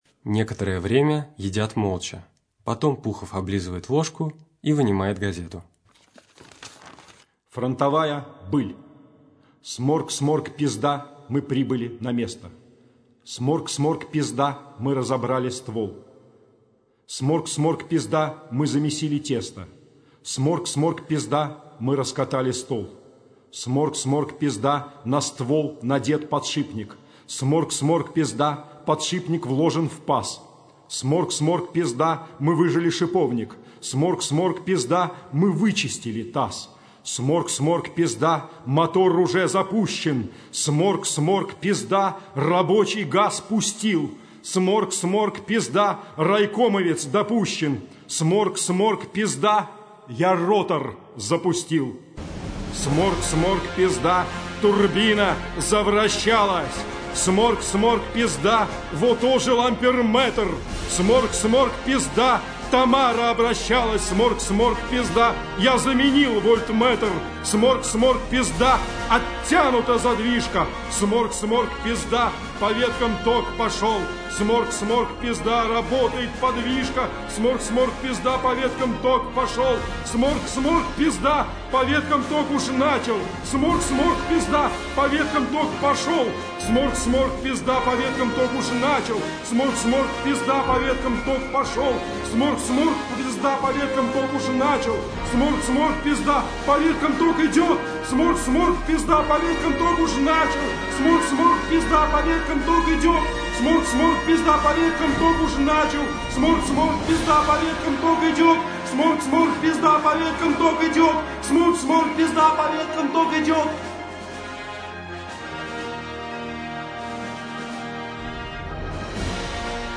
Радиопостановка пьесы Владимира Сорокина «Землянка», сделанная во время гастролей любительской театральной труппы журнала Game.EXE в кемеровском ДК «Металлург» в 2003 году.